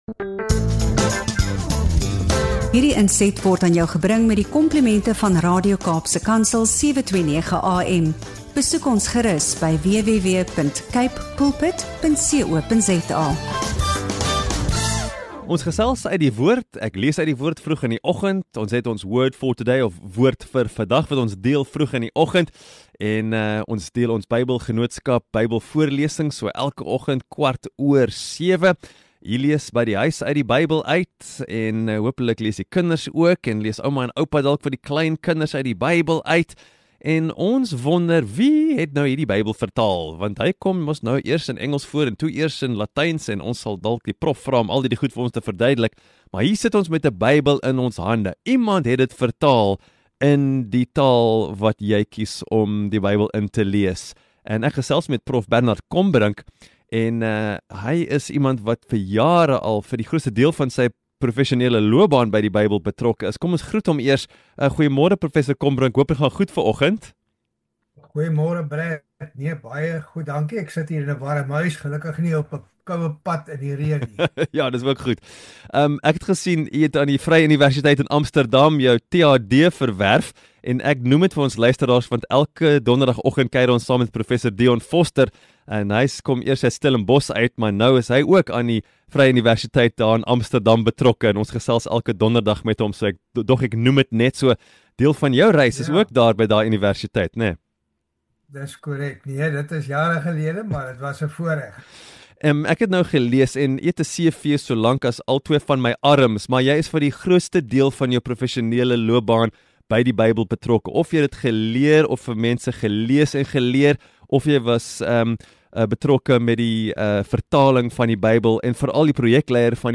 In hierdie boeiende podcastgesprek